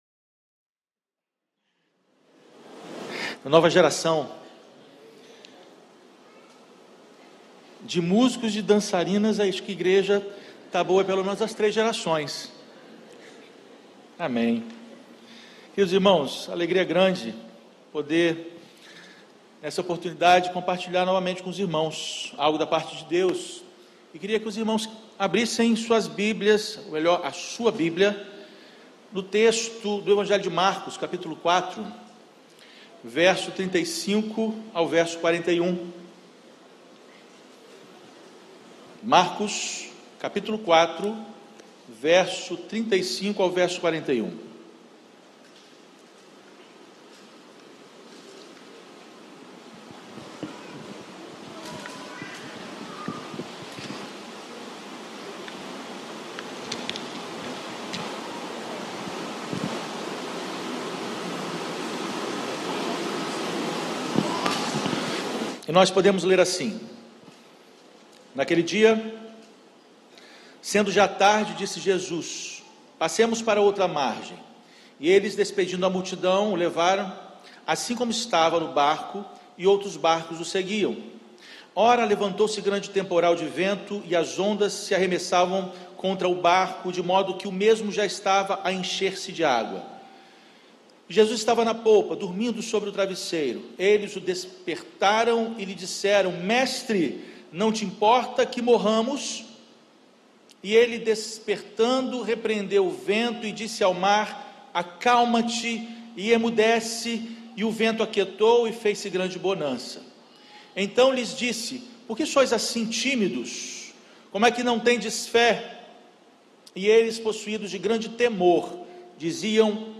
Primeira Igreja Batista do IPS